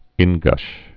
(ĭngsh, ĭng-)